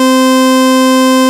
OSCAR 13 C5.wav